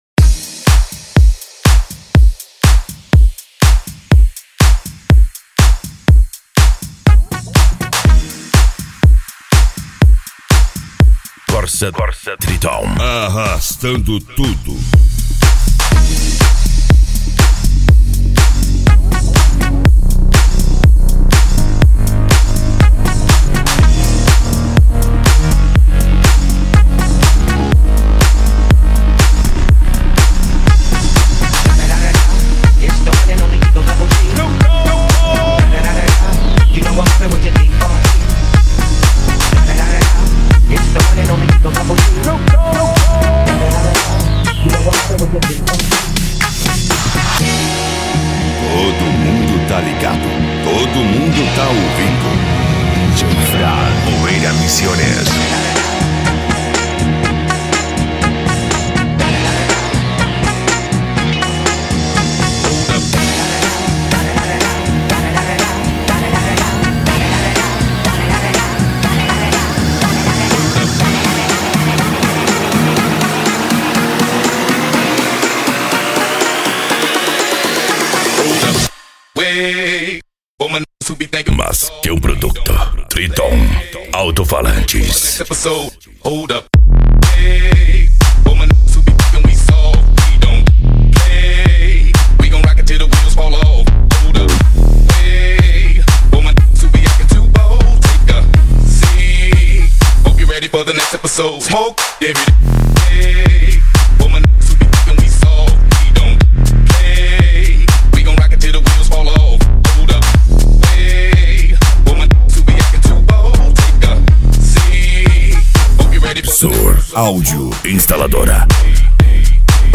Deep House
Electro House
Psy Trance
Remix